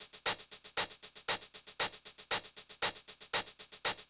04 Hihat.wav